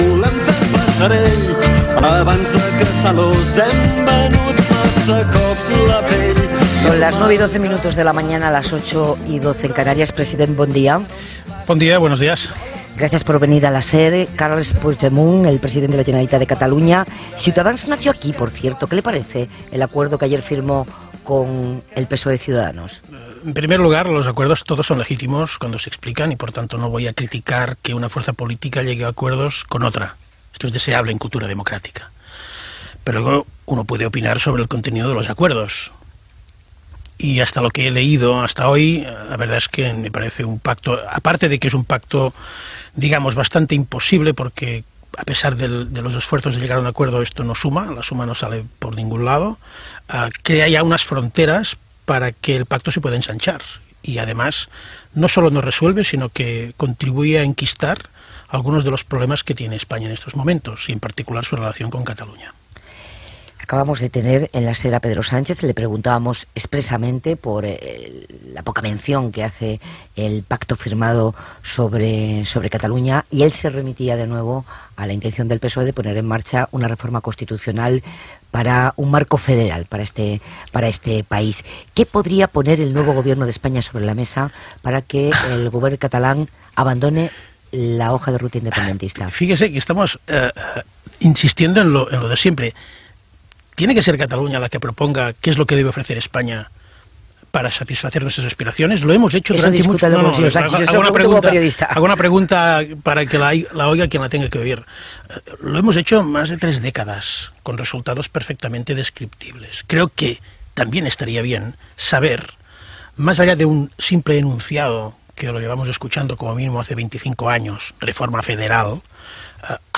Hora, entrevista al president de la Generalitat de Catalunya Carles Puigdemont sobre l'actualitat política
Info-entreteniment